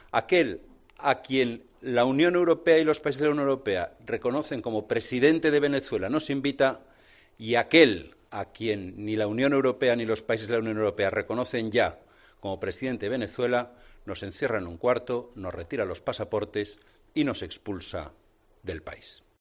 En rueda de prensa a su regreso tras ser expulsado de Venezuela, González Pons ha subrayado que el ministro de Asuntos Exteriores venezolano, Jorge Arreaza, al que ha calificado de "canciller fake" "debe ser sancionado por la UE" y ha pedido que no se le permita la entrada y que se le confisquen sus bienes.